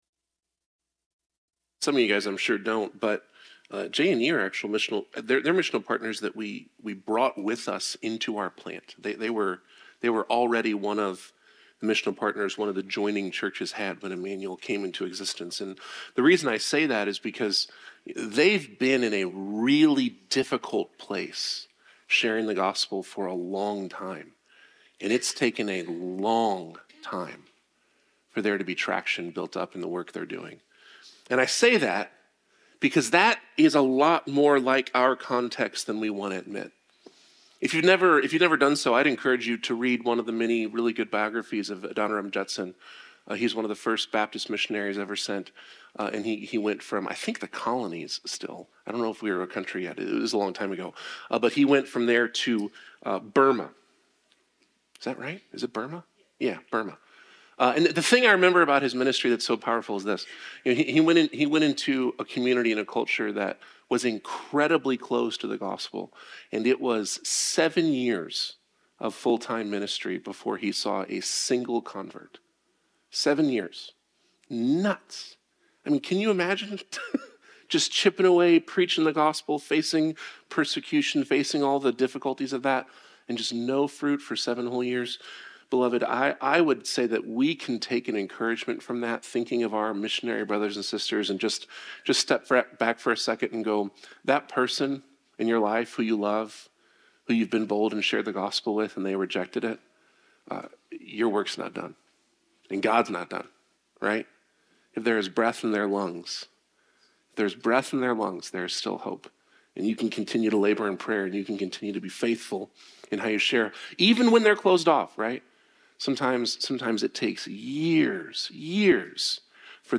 The sermon challenges the congregation to not measure their worth or happiness against earthly standards of fairness or success, but to embrace the truth that Jesus is enough for everyone.